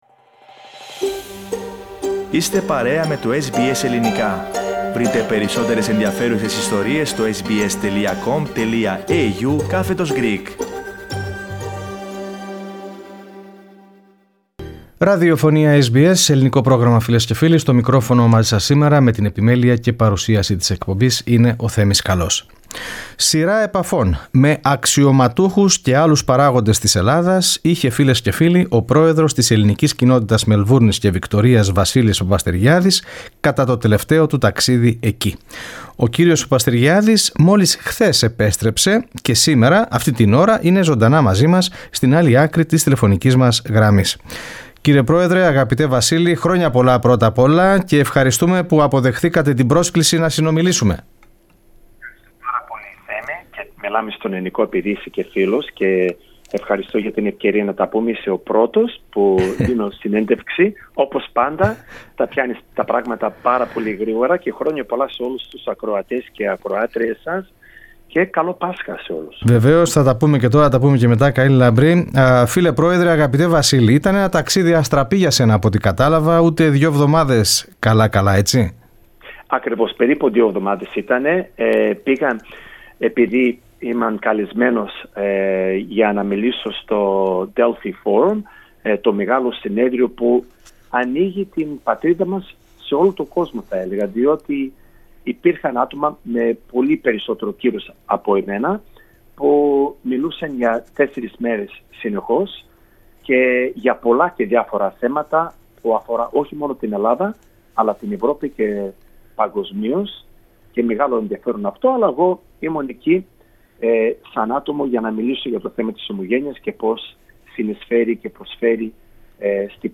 Ακούστε περισσότερα στο podcast της συνέντευξης.